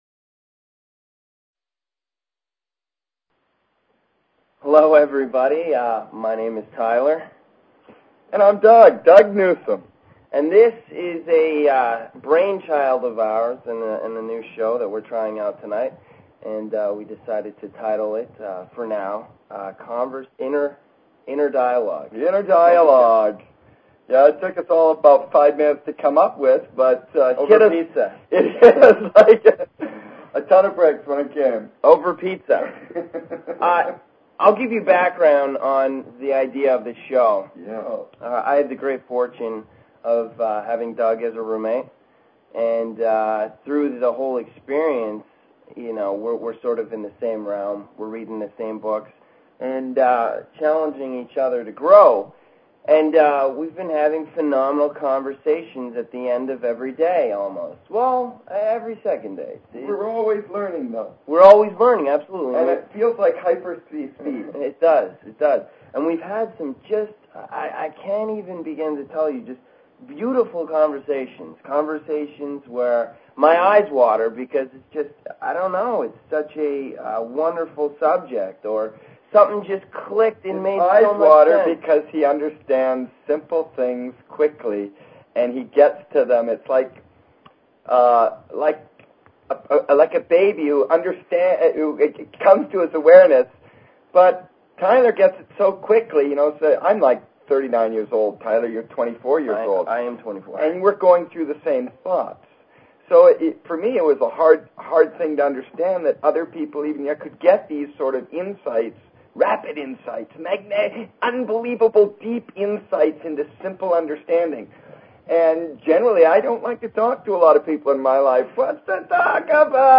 Talk Show Episode, Audio Podcast, Inner_Dialogue and Courtesy of BBS Radio on , show guests , about , categorized as